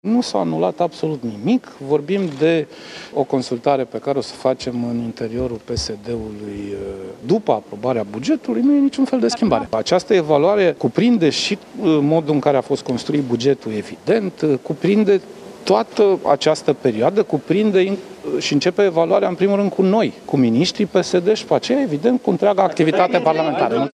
Sorin Grindeanu a menționat că în partid continuă consultările legate de actuala formulă de guvernare și a menționat că în coaliție nu s-a discutat astăzi despre înlăturarea lui Ilie Bolojan din funcția de premier: